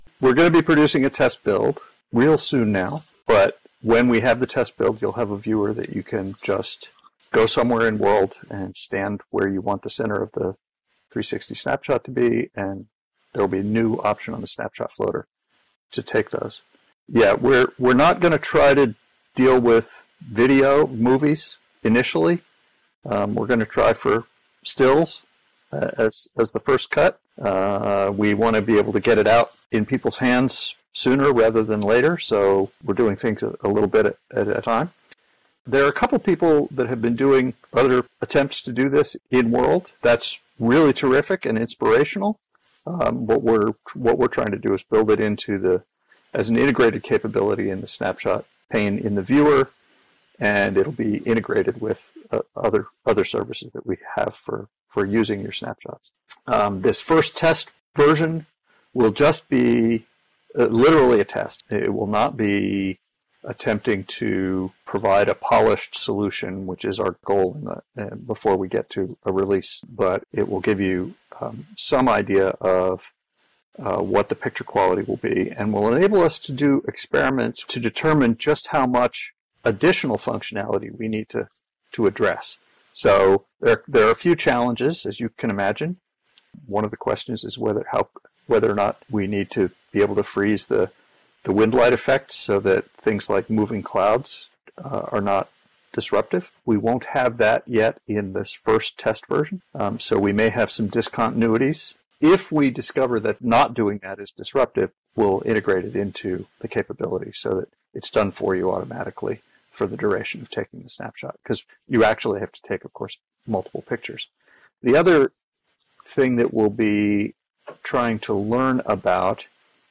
Note: the audio clips here are extracts of salient points from the discussion on the 360 Snapshot capability.